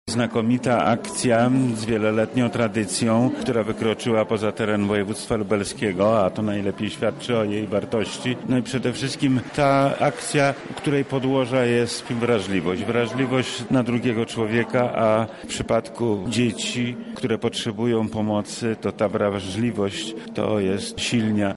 -mówi wojewoda lubelski Lech Sprawka.